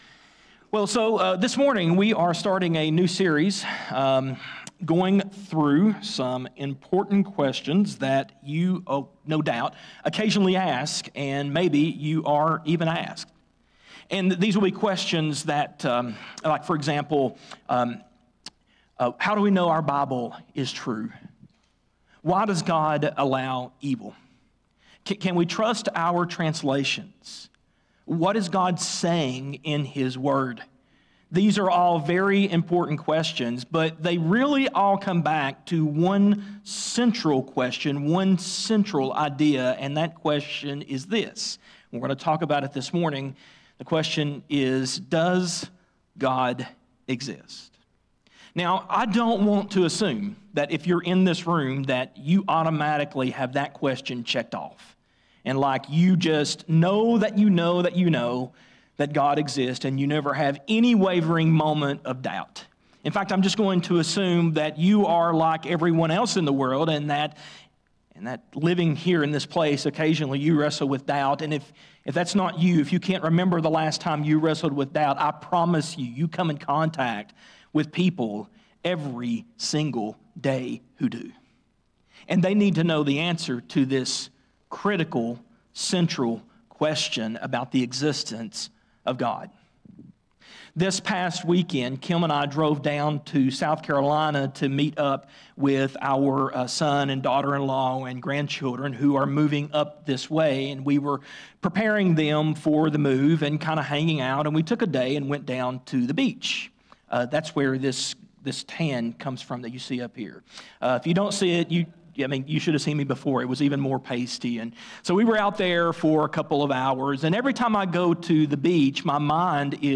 A message from the series "Faith For A Reason." Throughout history, people have used the Bible to defend terrible actions—to justify slavery, excuse injustice, defend immoral behavior, and condemn others. How does a book that reveals God’s perfect love end up twisted to rationalize such harm?